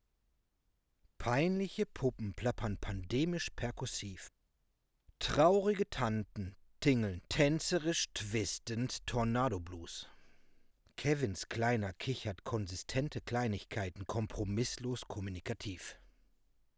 Wie unschwer zu erkennen ist, besitze ich ein RODE NT USB. Ploppschutz ist zur Zeit der Aufnahme doppelt.
Plopptest.wav
Moin, na das klingt doch schon ganz anders. klingt sehr gut.
Keine fiesen Resonanzen und nicht überbedämft.